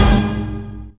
SWINGSTAB 6.wav